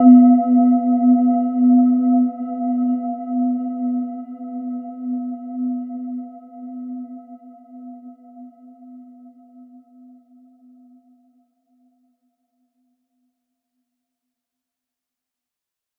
Gentle-Metallic-4-B3-f.wav